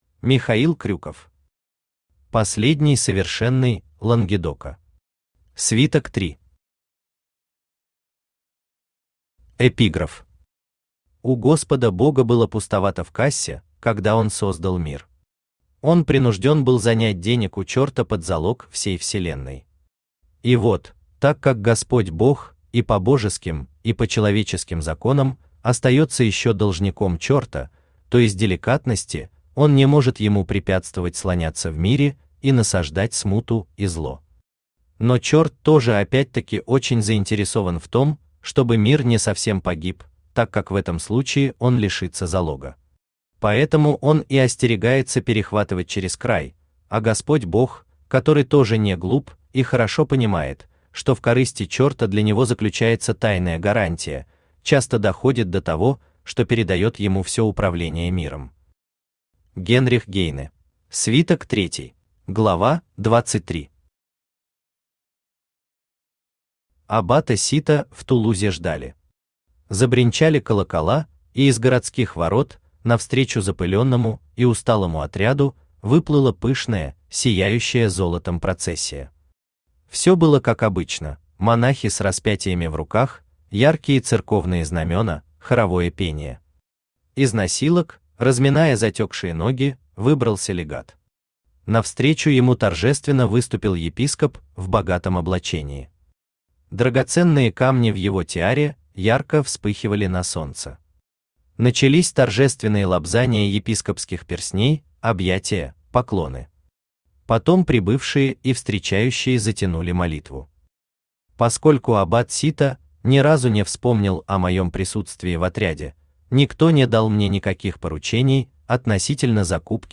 Аудиокнига Последний Совершенный Лангедока. Свиток 3 | Библиотека аудиокниг
Свиток 3 Автор Михаил Крюков Читает аудиокнигу Авточтец ЛитРес.